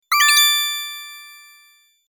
hell-yeah-somewhat-calmer.mp3